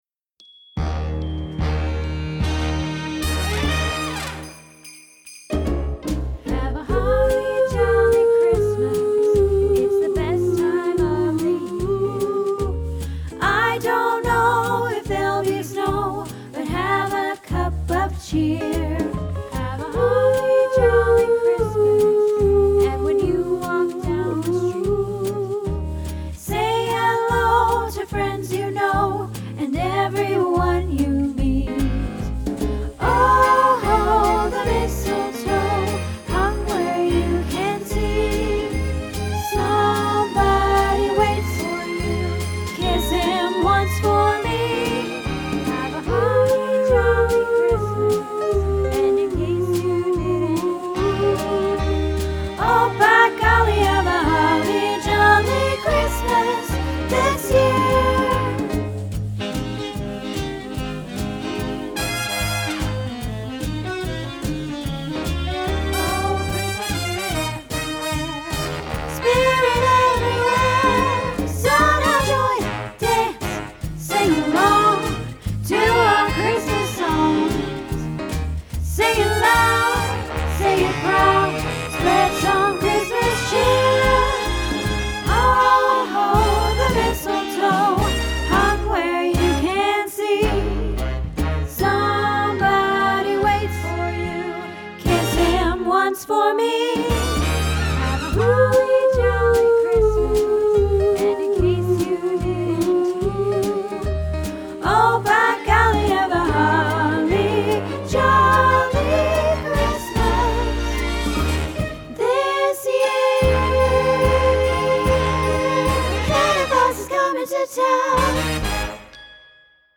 Holly Jolly Christmas - Soprano